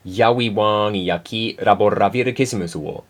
LVFNativeNamePronounce.ogg